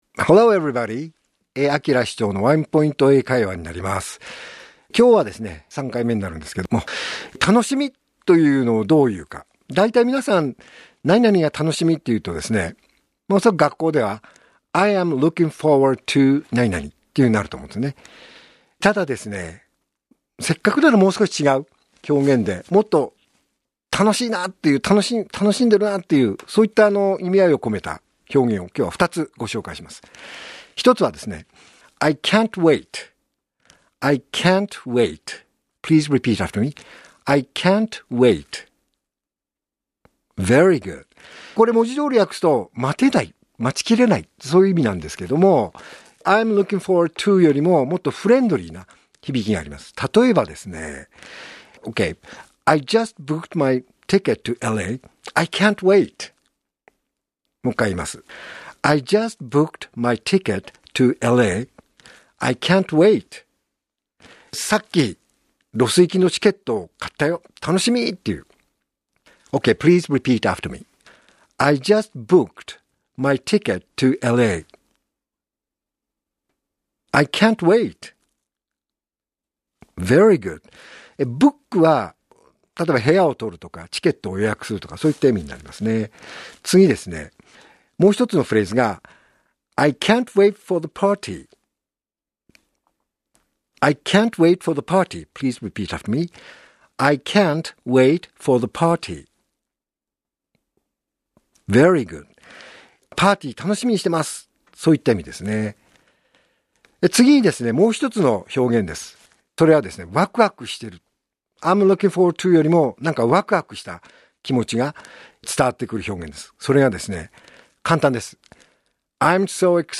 R5.2 AKILA市長のワンポイント英会話